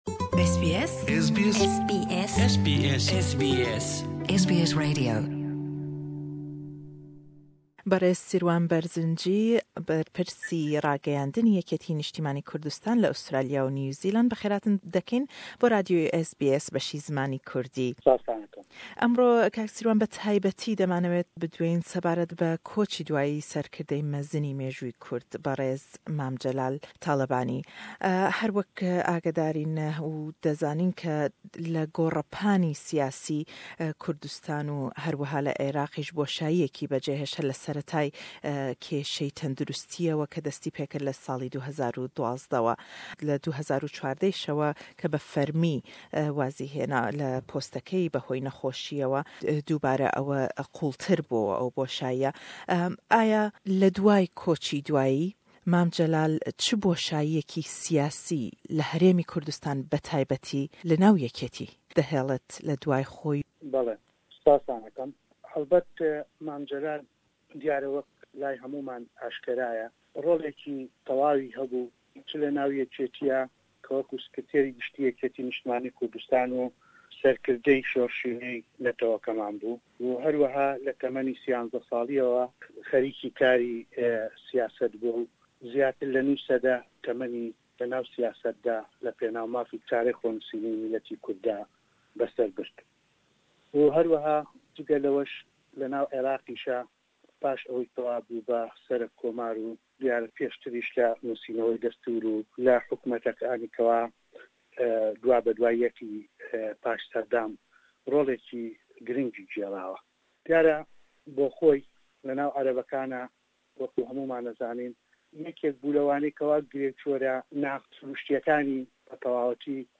Lêdwanêk